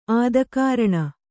♪ ādakāraṇa